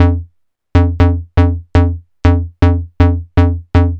TSNRG2 Bassline 033.wav